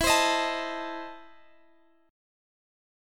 Listen to EM7sus4#5 strummed